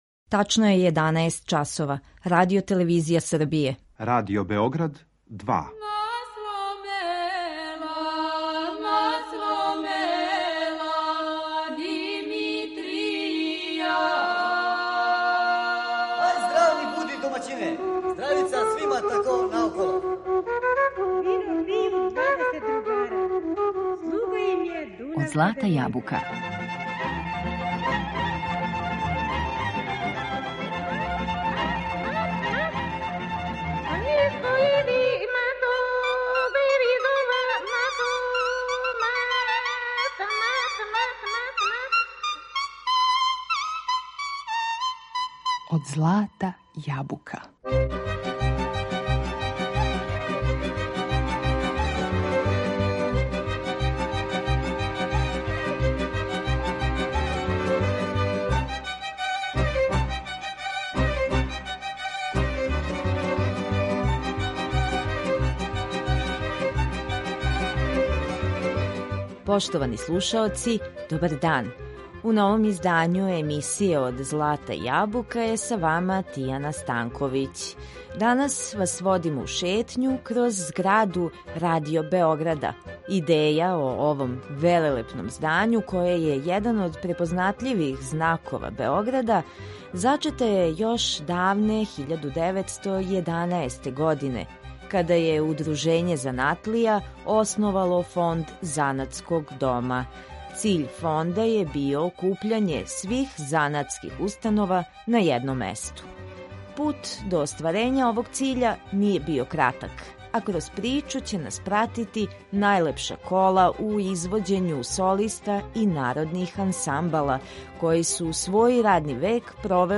Пут до остварења овог циља није био кратак, а кроз причу ће нас пратити најлепша кола у извођењу солиста и народних ансамбала, који су свој радни век провели у просторијама на адреси Хиландарска 2.